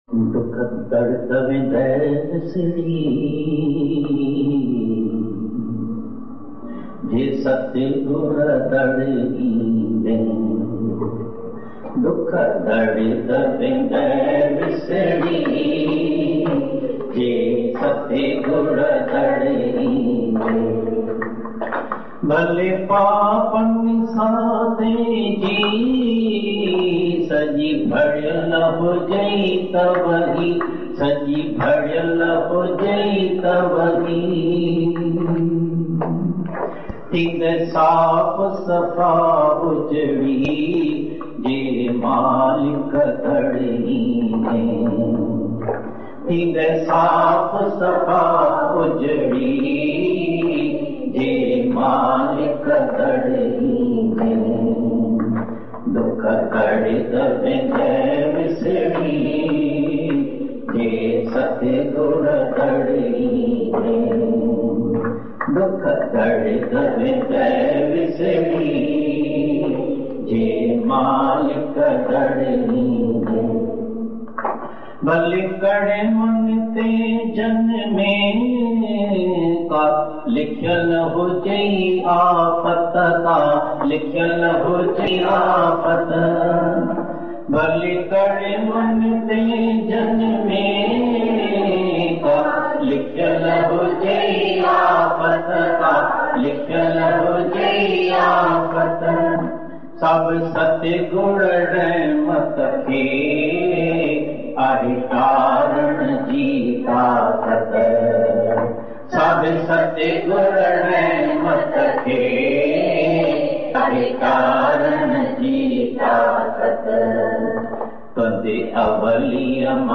Dukh dard vendai visri Bhajan | दुख दर्द वेन्दई विसरी भजनDivine Meera Bhagwan Bhajans